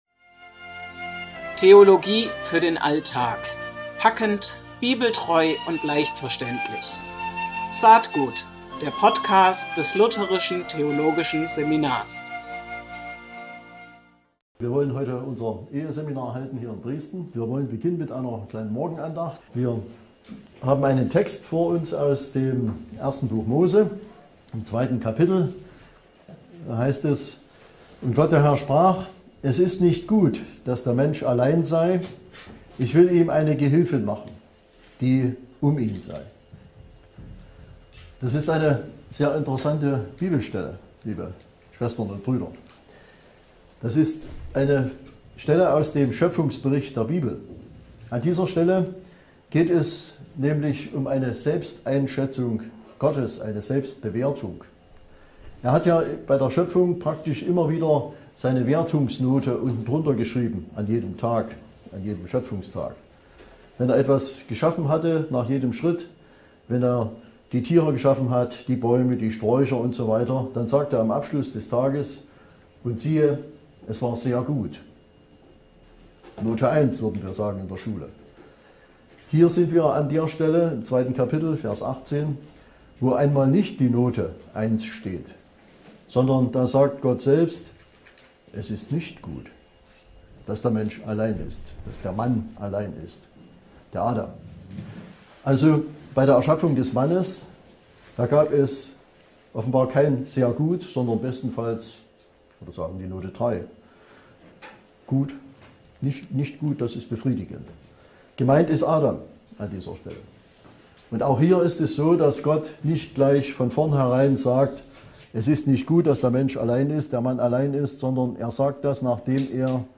Es ist nicht gut, dass der Mensch allein sei - Andacht zu 1. Mose 2, 18 ~ Saat-Gut (Lutherische Theologie für den Alltag) Podcast